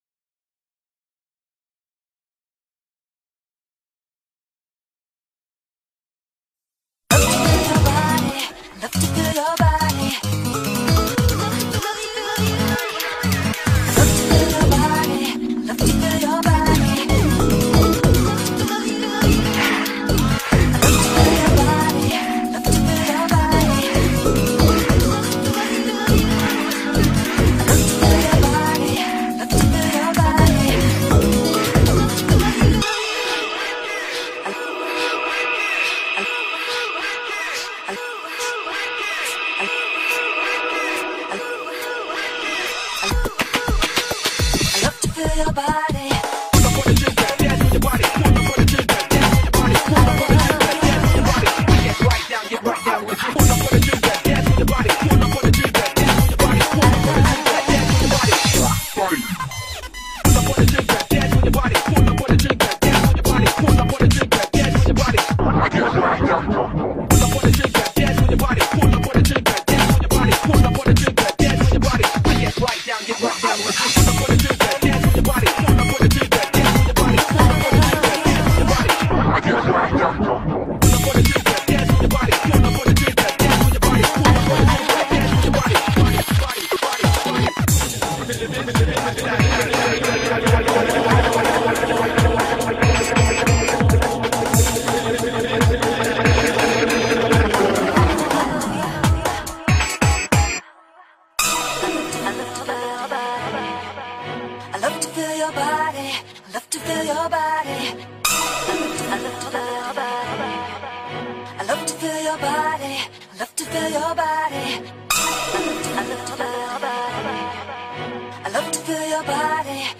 High tempo beats, perfect to rollerblade in the summer...